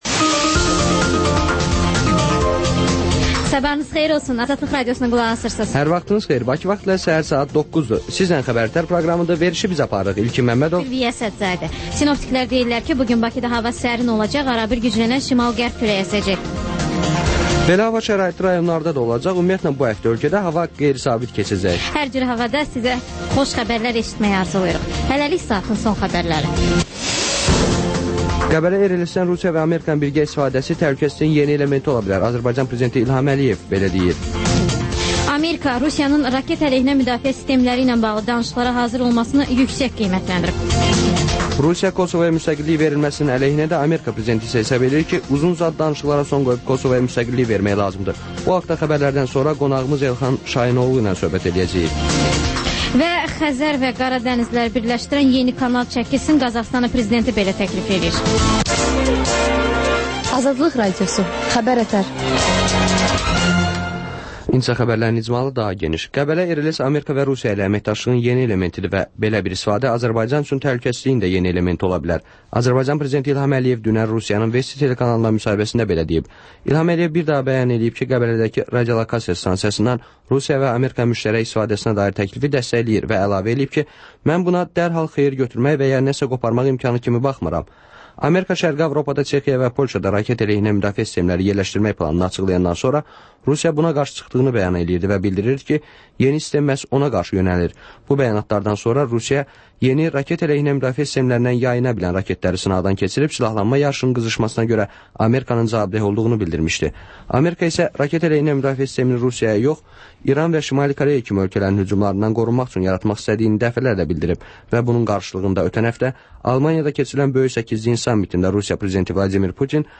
Xəbər-ətər: xəbərlər, müsahibələr, sonra PANORAMA verilişi: Həftənin aktual mövzusunun müzakirəsi